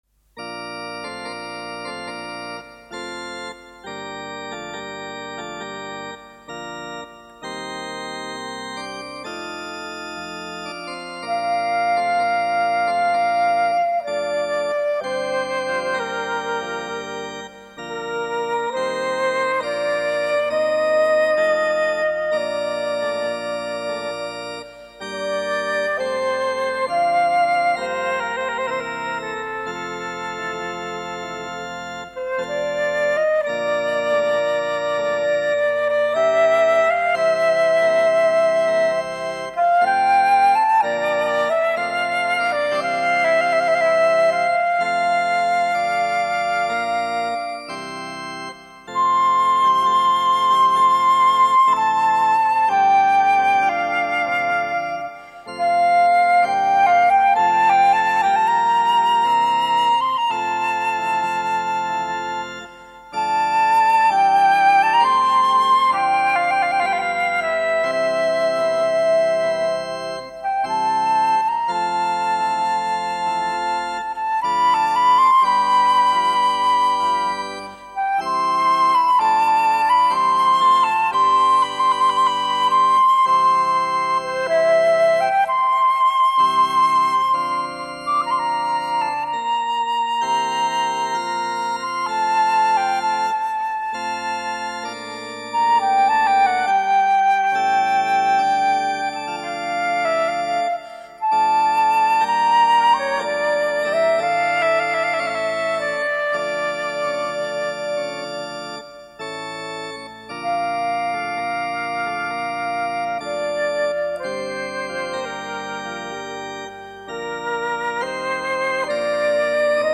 református ének